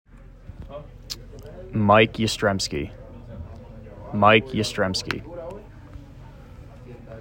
yuh-strem-skee, referred to colloquially as “Yaz”) as an honoree of the prestigious Bob Feller Act of Valor Award for the 2025 season.